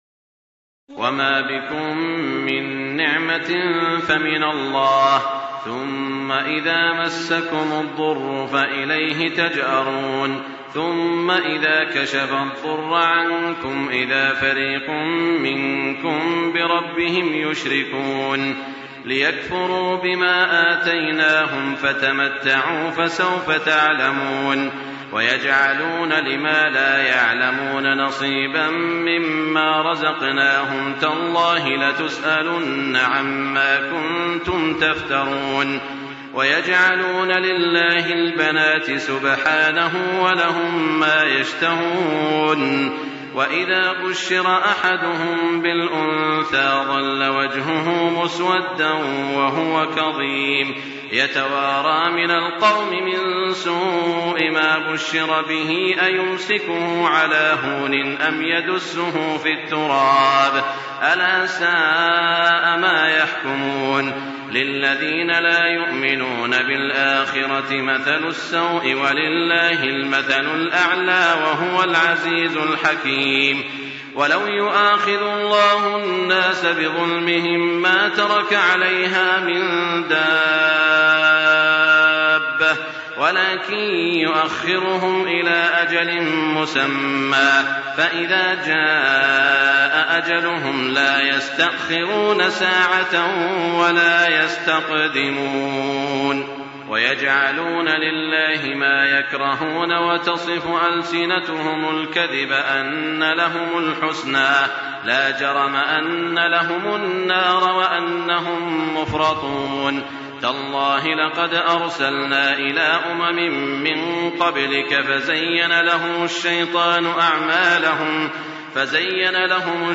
تراويح الليلة الثالثة عشر رمضان 1424هـ من سورة النحل (53-128) Taraweeh 13 st night Ramadan 1424H from Surah An-Nahl > تراويح الحرم المكي عام 1424 🕋 > التراويح - تلاوات الحرمين